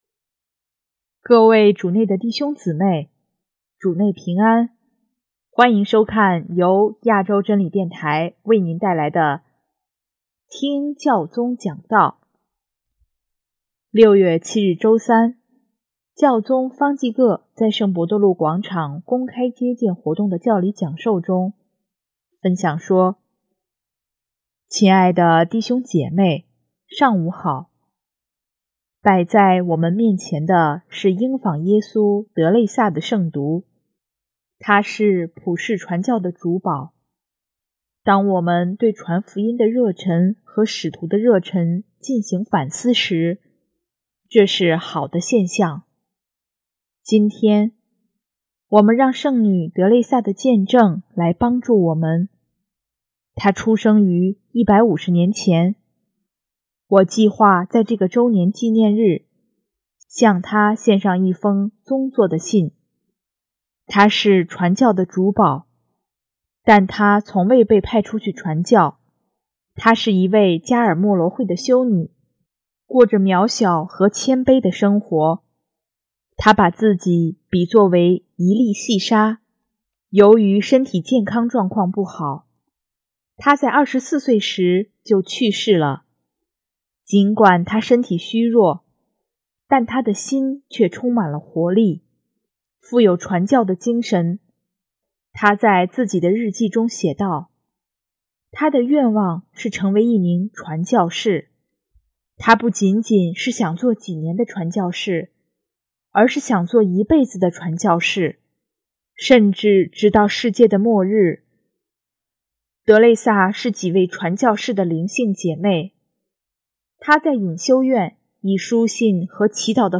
6月7日周三，教宗方济各在圣伯多禄广场公开接见活动的教理讲授中，分享说：